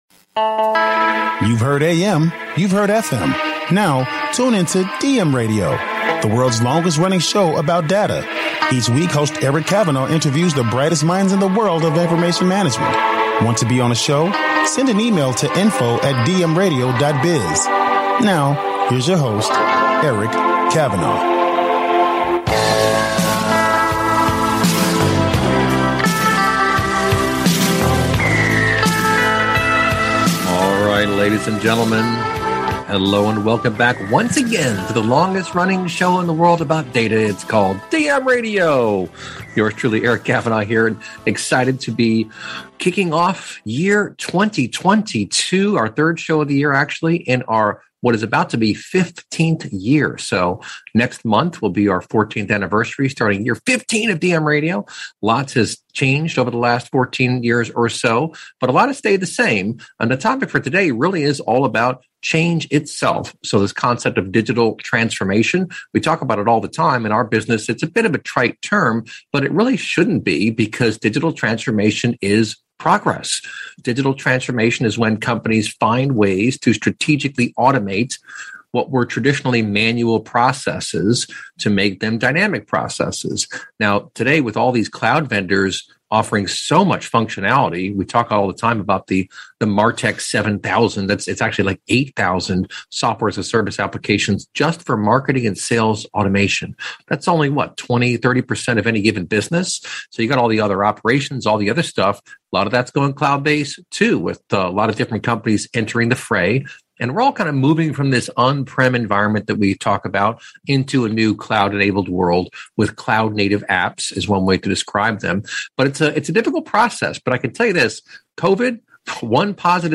sSnce February, 2008, we’ve featured the brightest minds in the world of data and analytics, interviewing thousands of experts.